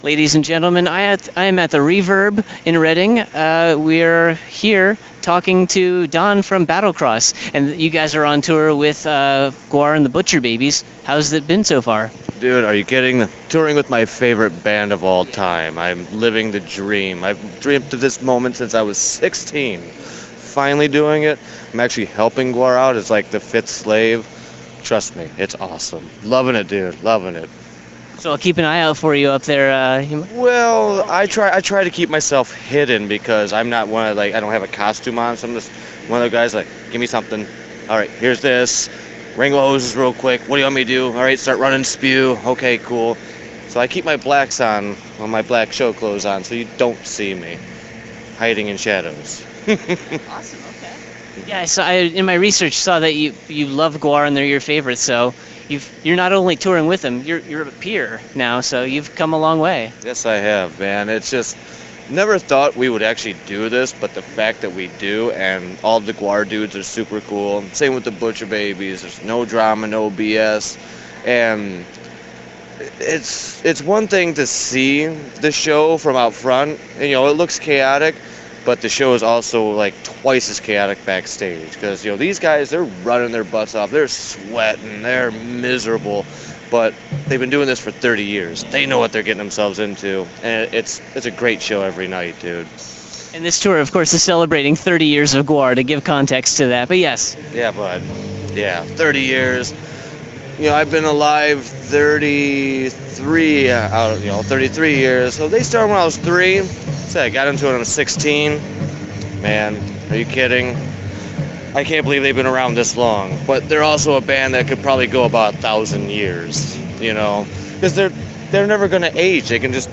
Exclusive: Battlecross Interview
63-interview-battlecross.mp3